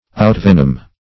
Outvenom \Out*ven"om\